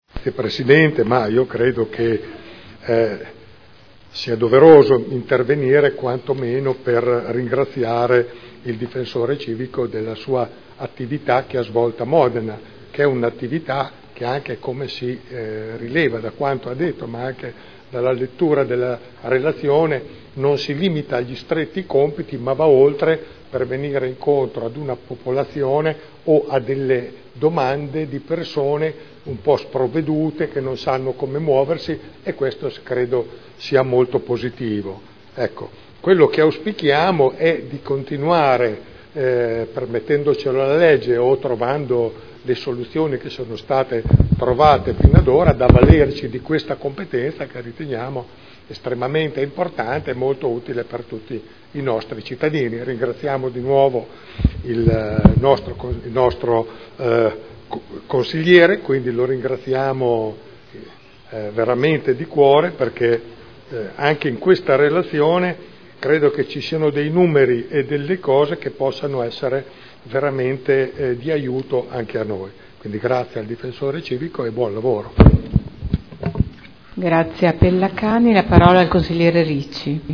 Seduta del 24 febbraio. Relazione del Difensore Civico al Consiglio Comunale sull’attività svolta nell’anno 2013. Dibattito